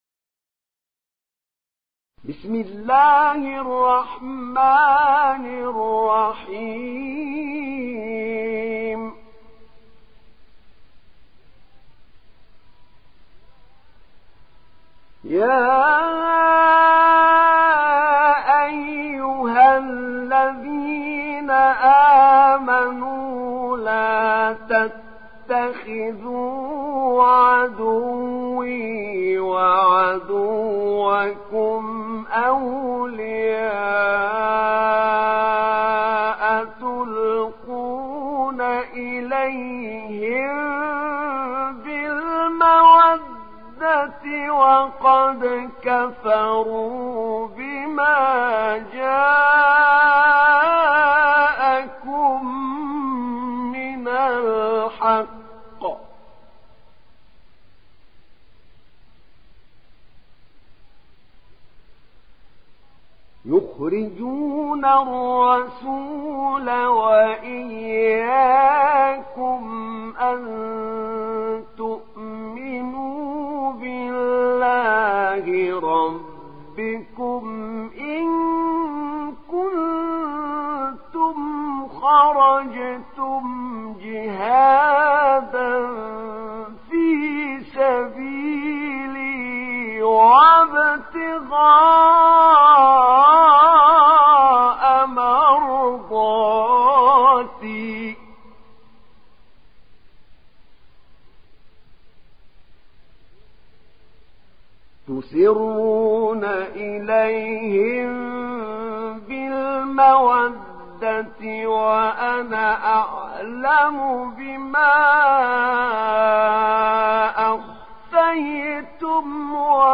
تحميل سورة الممتحنة mp3 بصوت أحمد نعينع برواية حفص عن عاصم, تحميل استماع القرآن الكريم على الجوال mp3 كاملا بروابط مباشرة وسريعة